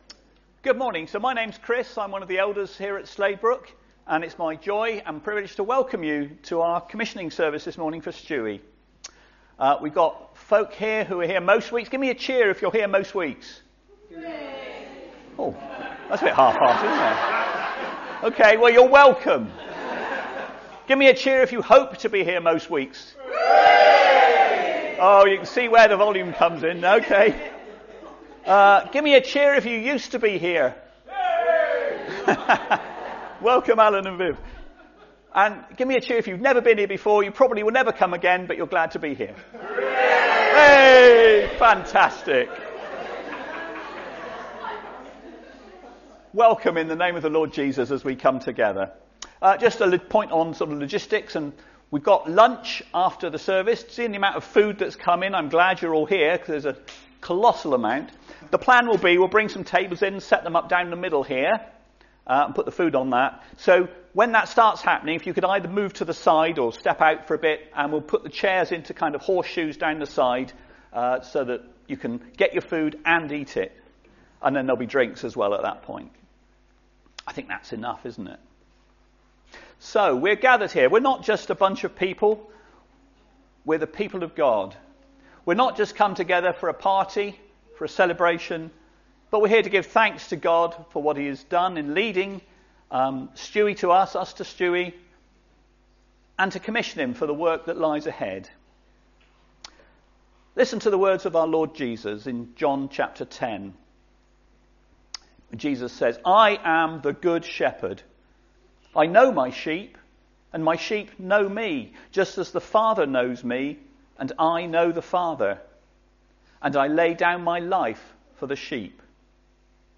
You can listen back to his commissioning service .